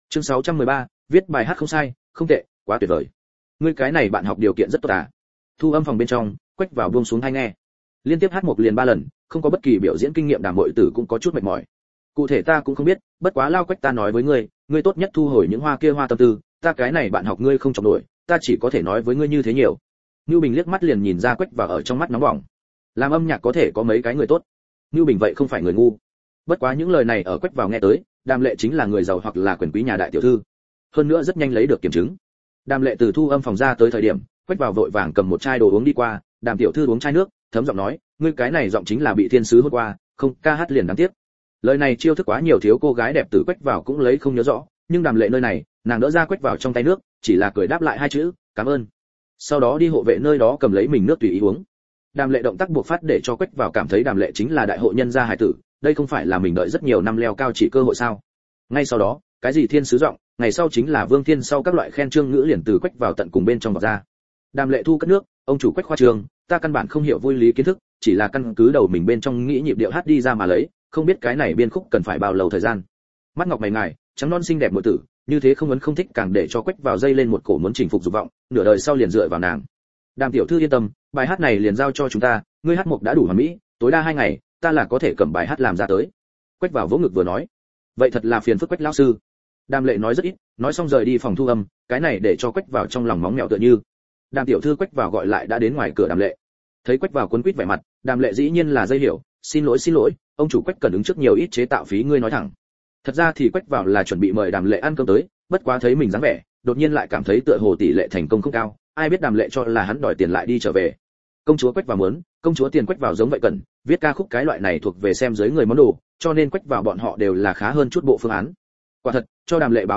Ta Trọng Sinh Đến Năm 2002 Audio - Nghe đọc Truyện Audio Online Hay Trên TH AUDIO TRUYỆN FULL